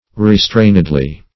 restrainedly - definition of restrainedly - synonyms, pronunciation, spelling from Free Dictionary Search Result for " restrainedly" : The Collaborative International Dictionary of English v.0.48: Restrainedly \Re*strain"ed*ly\, adv.
restrainedly.mp3